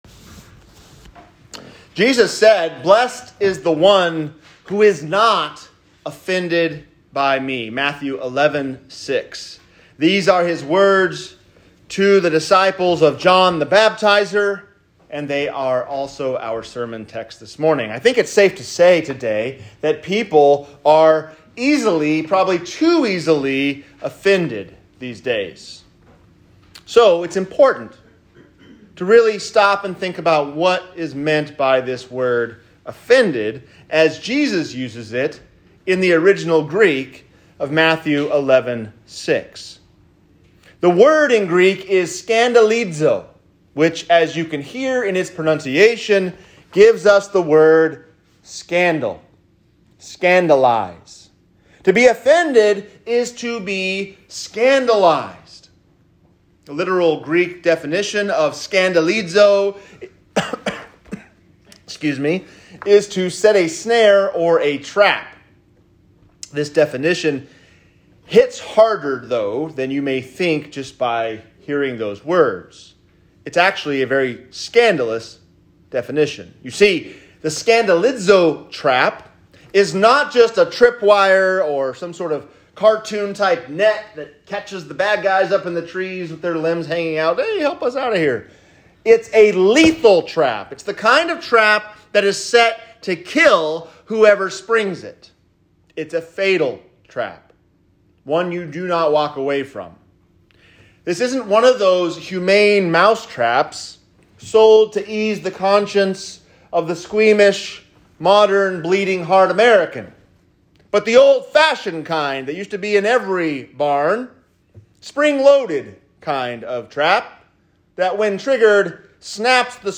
Blessed Not Offended | Sermon